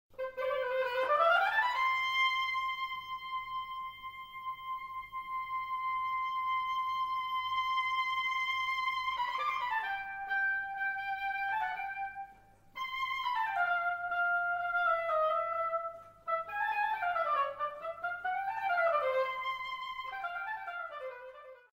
OBOE (viento madera)